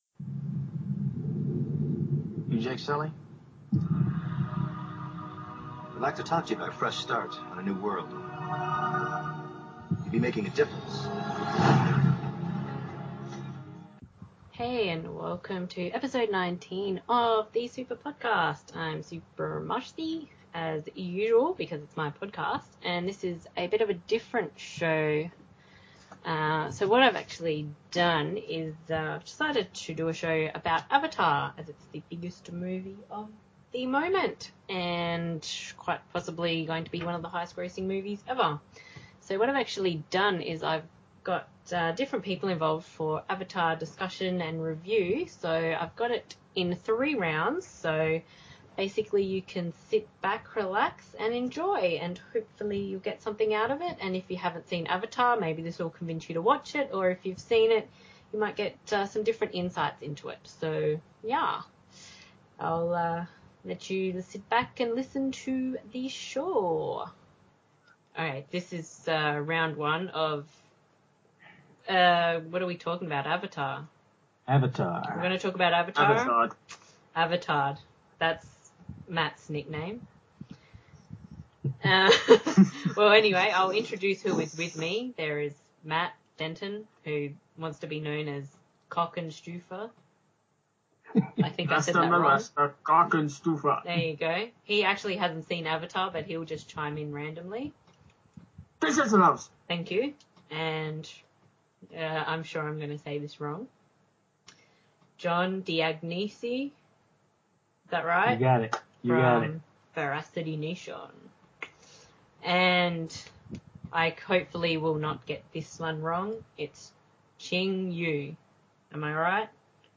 This is the Avatar edition and I decided to find a few different people to discuss the film with, and instead of having everyone all talk at once, it is split into 3 rounds with the discussion on the film being different for all 3 rounds.